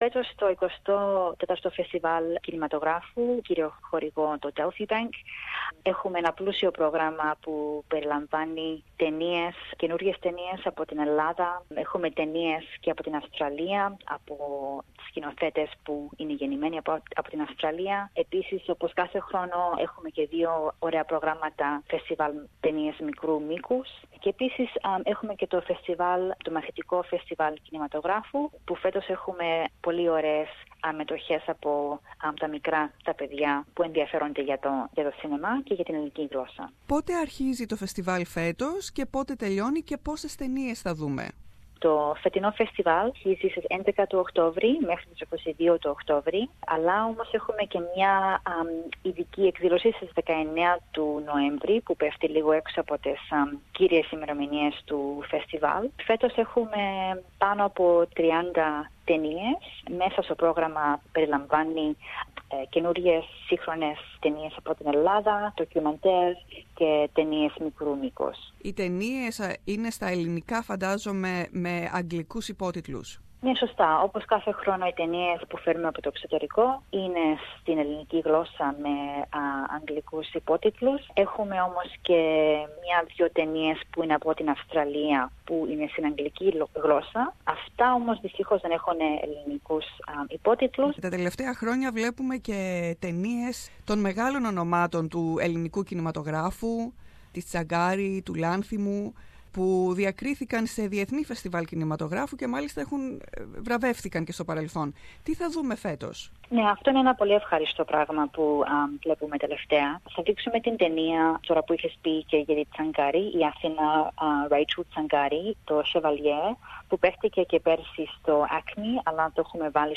Περισσότερα ακούμε στην συζήτηση